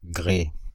Ääntäminen
Ääntäminen Paris: IPA: [ɡʁe] France (Île-de-France): IPA: /ɡʁe/ Haettu sana löytyi näillä lähdekielillä: ranska Käännös Konteksti Substantiivit 1. грей metrologia Suku: m .